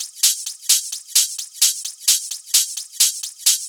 Flanged Hats 01.wav